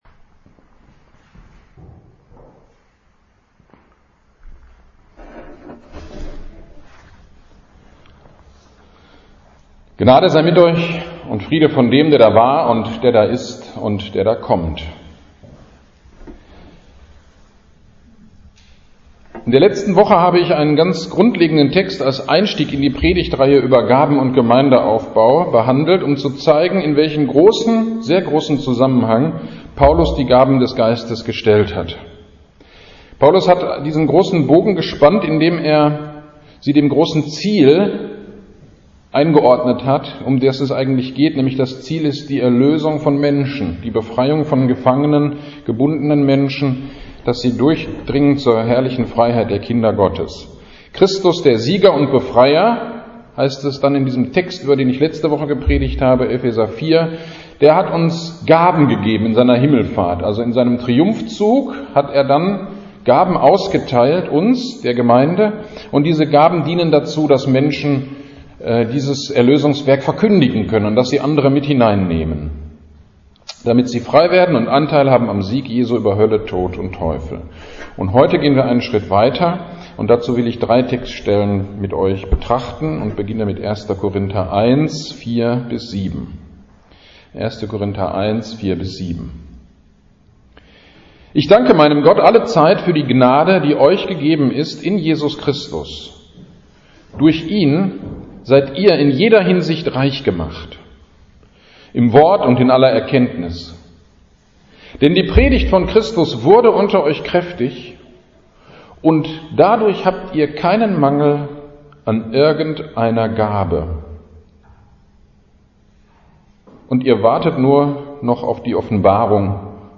Predigt 2 der Predigtreihe zu „Gaben und Gemeindebau“